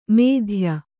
♪ mēdhya